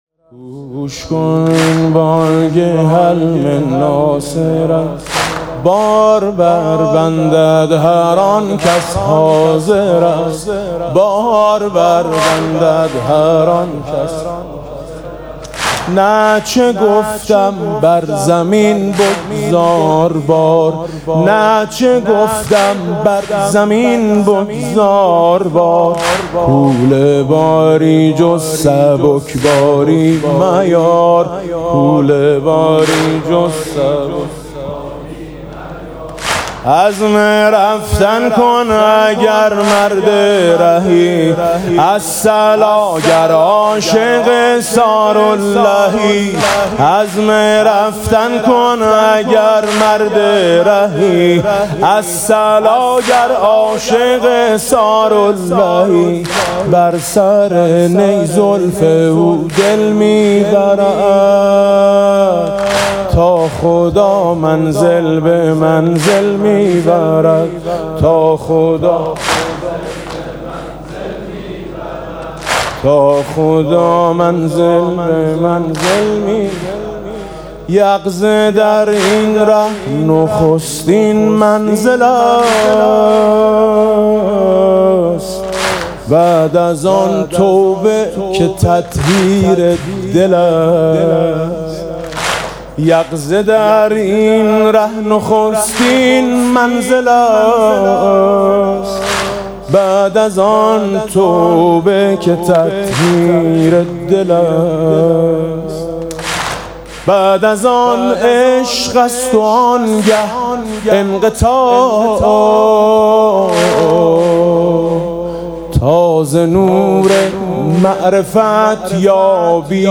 مراسم عزاداری شب ششم محرم ۱۴۰۳ با صدای میثم مطیعی
تا خدا؛ منزل به منزل با حسین - منزل پنجم: معرفت (واحد)